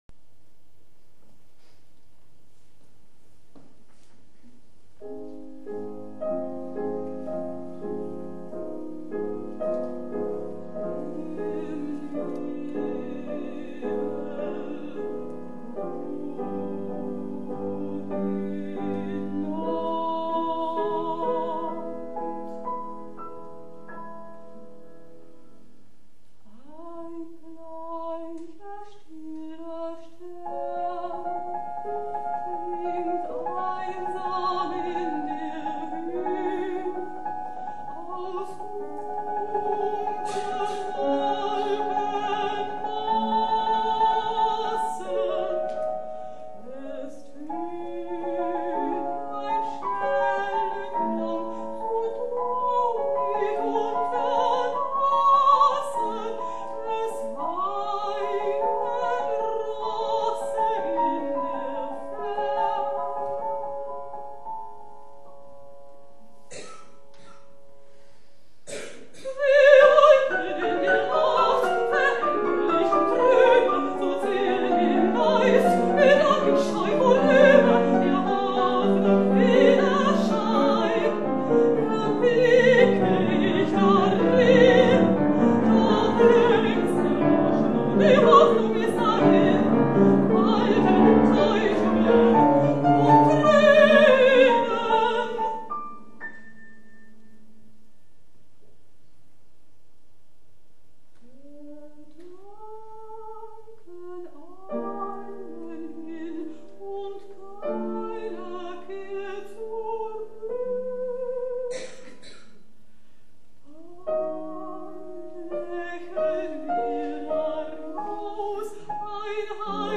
Liederabend „Ich bin der Welt abhanden gekommen“ im
E.-Winterstein-Theater Annaberg-Buchholz
Piano